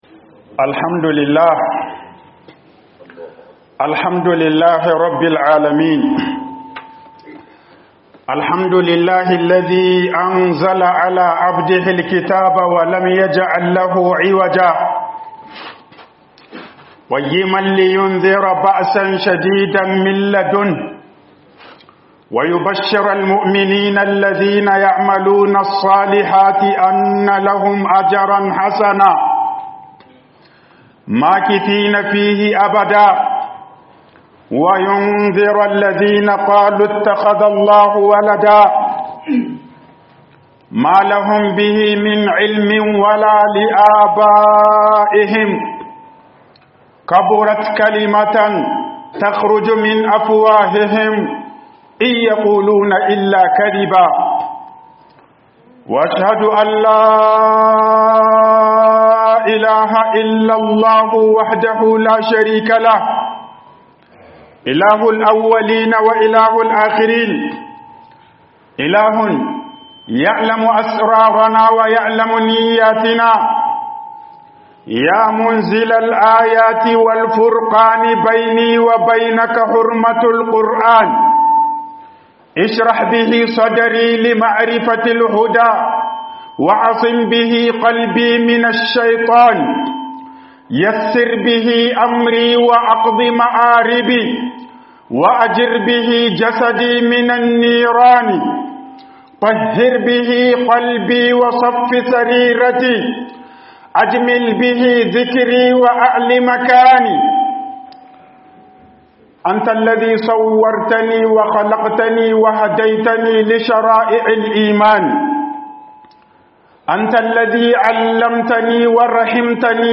MUHIMMANCIN KARANTA ALKUR'ANI A WATAN RAMADAN 01 - HUƊUBOBIN JUMA'A